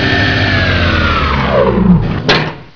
Engine4
ENGINE4.WAV